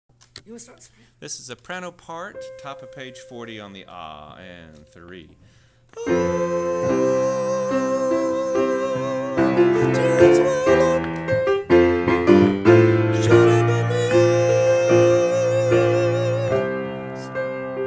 Not Guilty individual voice parts